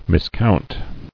[mis·count]